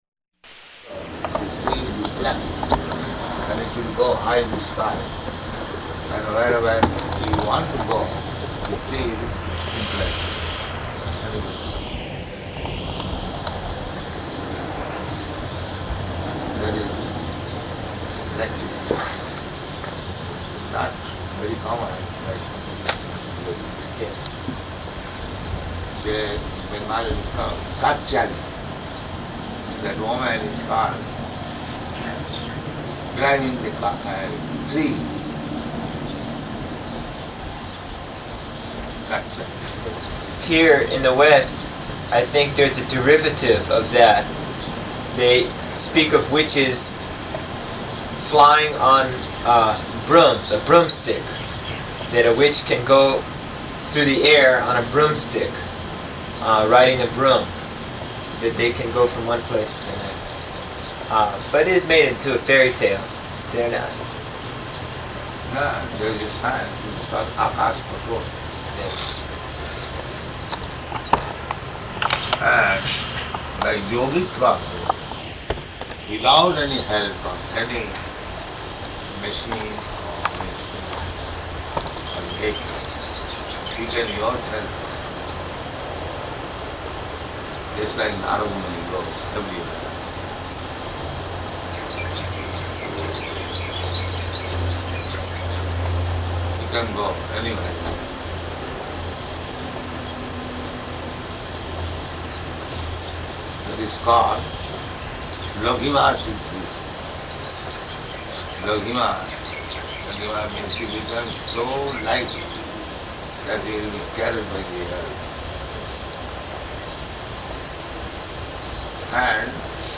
Room Conversation
Room Conversation --:-- --:-- Type: Conversation Dated: June 16th 1975 Location: Honolulu Audio file: 750616R2.HON.mp3 Prabhupāda: ...and if you achieve this platform.